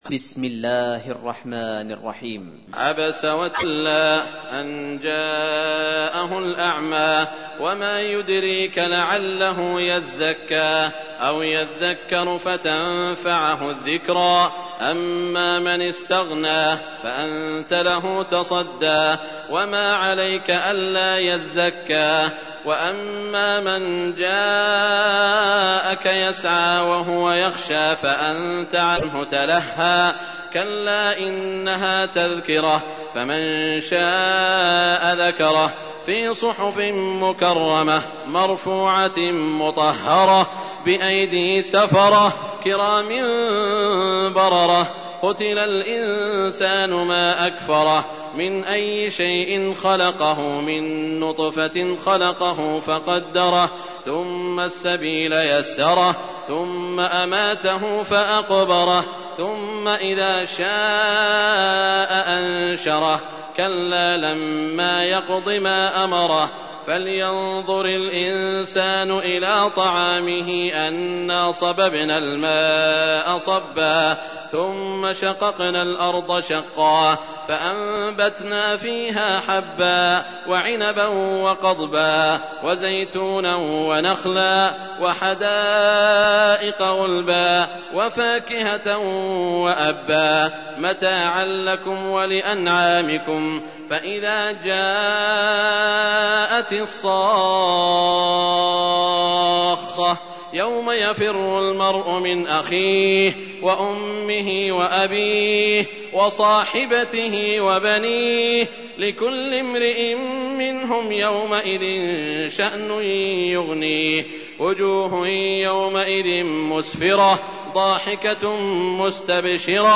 تحميل : 80. سورة عبس / القارئ سعود الشريم / القرآن الكريم / موقع يا حسين